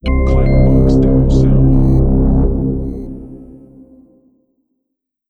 “Moltrix” Clamor Sound Effect
Can also be used as a car sound and works as a Tesla LockChime sound for the Boombox.